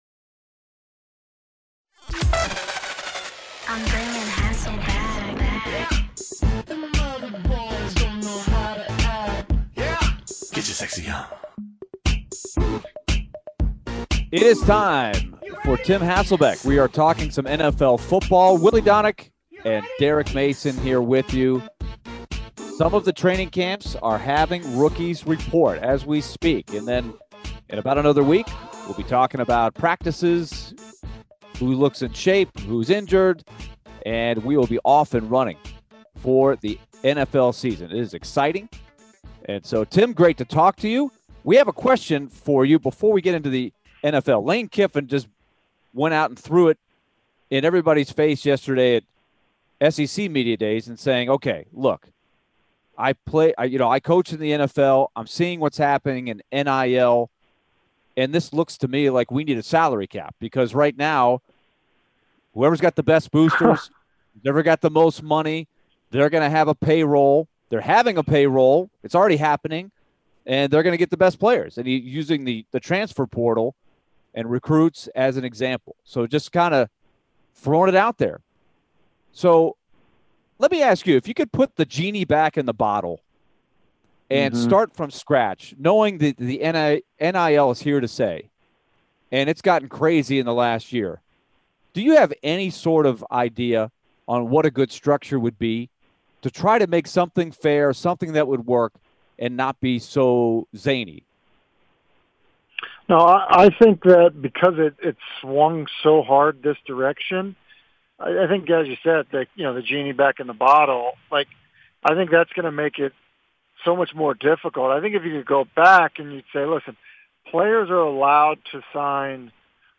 Tim Hasselbeck Full Interview (07-19-22)